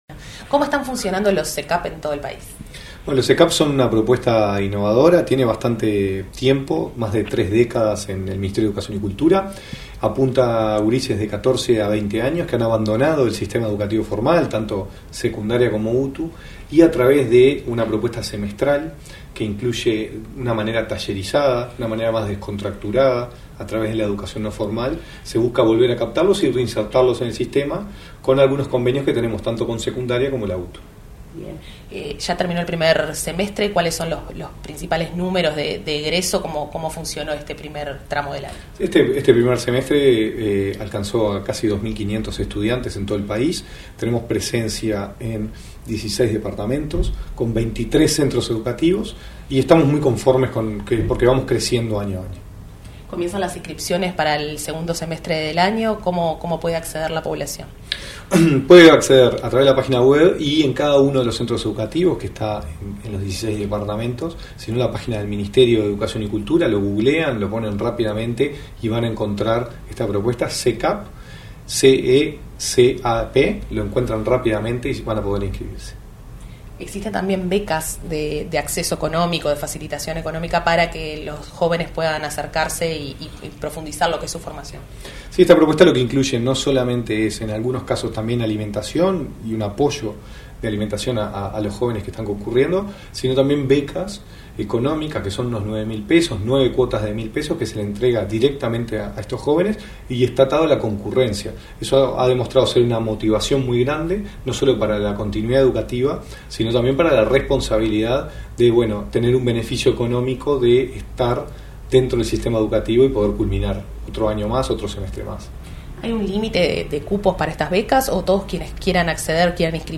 Entrevista al director nacional de Educación, Gonzalo Baroni